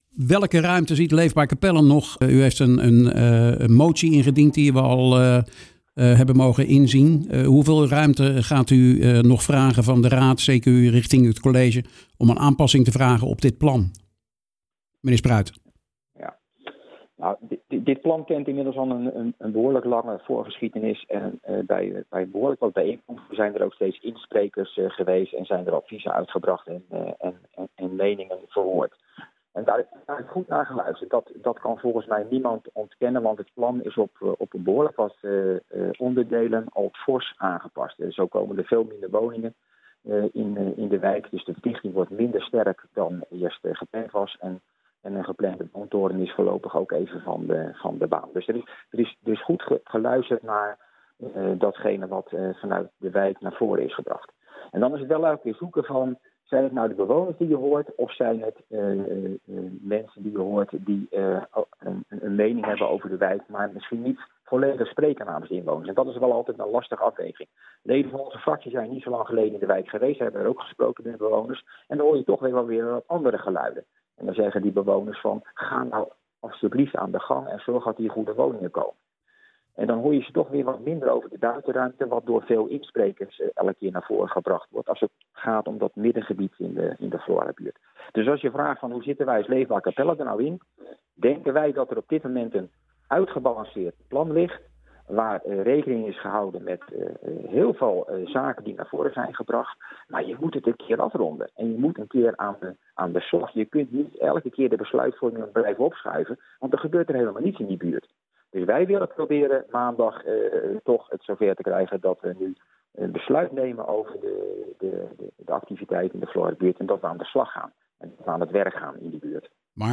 praat met de raadsleden Gerrit Spruit van Leefbaar Capelle en Aad Terlouw van de SGP.